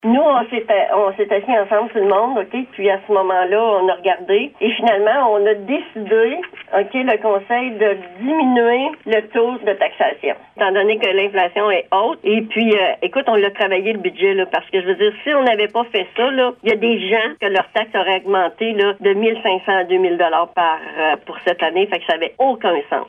La mairesse de Grand-Remous, Jocelyne Lyrette, explique le travail qui a dû être fait par le conseil municipal :